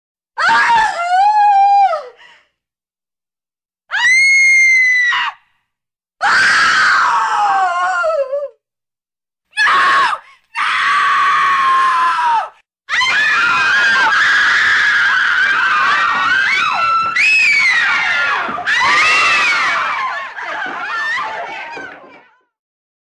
Suara Teriakan Wanita
Kategori: Suara manusia
Keterangan: Efek suara jeritan/ teriakan wanita dapat digunakan untuk kebutuhan download, editing video, atau dubbing. Sumber ini ideal untuk menciptakan suasana tegang, horor, atau dramatis dalam proyek video atau audio Anda.
suara-teriakan-wanita-id-www_tiengdong_com.mp3